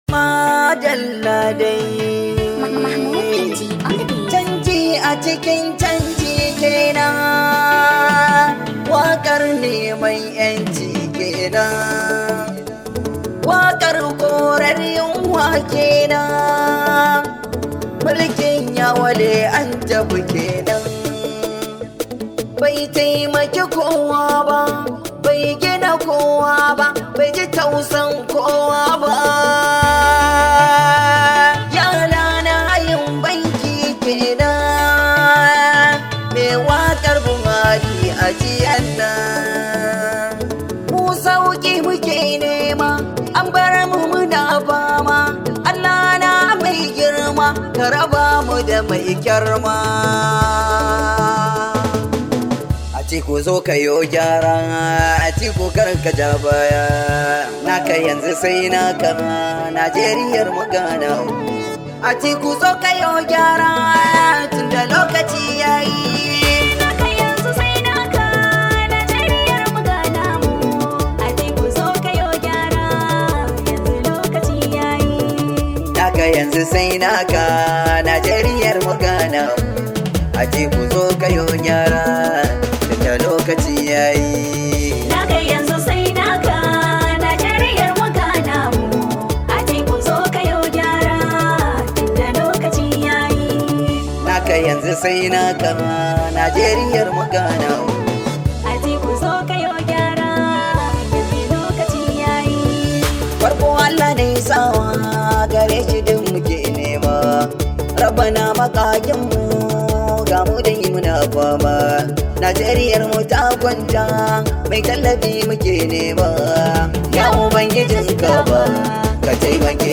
Politics Musics